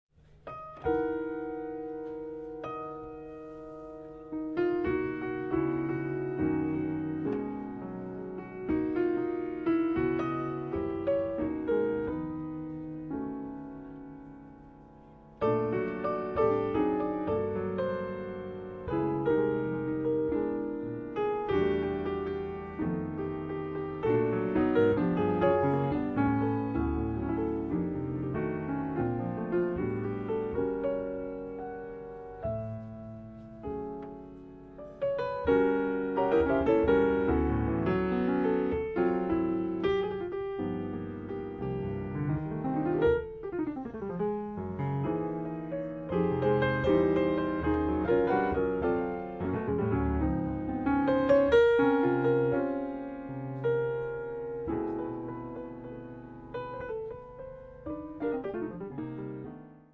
contrabbasso
pianoforte
batteria